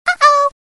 Звуки уведомлений Telegram
Звук ICQ для Telegram